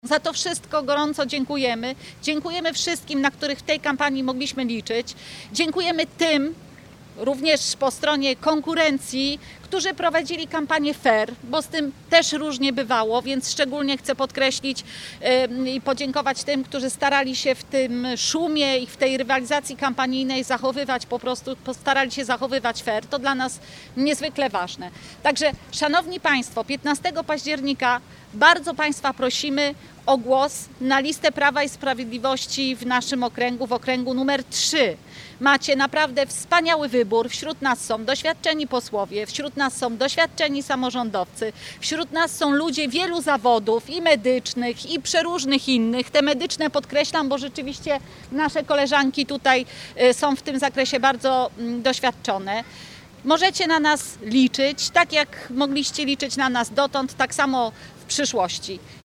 Na Placu Gołębim we Wrocławiu zgromadzili się senatorowie i posłowie, a także osoby ubiegające się o mandat do parlamentu.